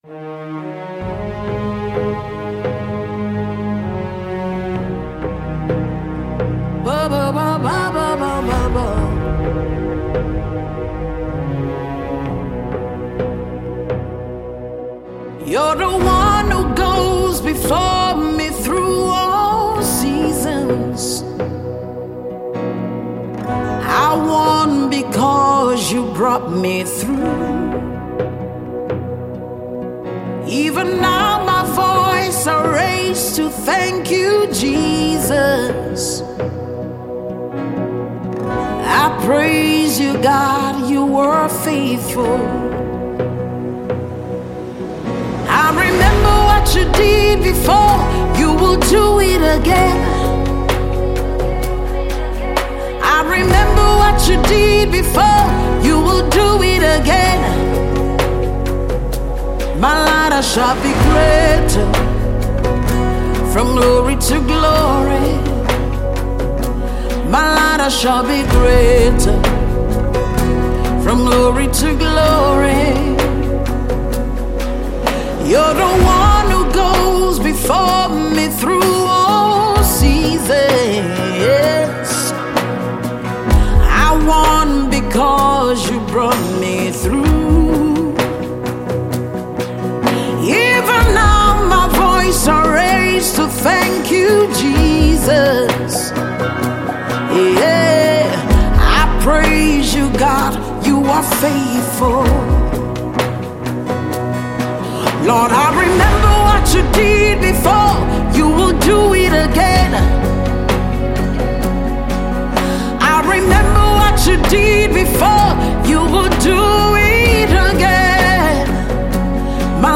Well renowned Gospel Artist
thrilling new gbedu song